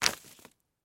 На этой странице представлена коллекция звуков канатов, верёвок и тросов: скрип при натяжении, шуршание, удары о поверхность.
Звук упавшей на землю веревки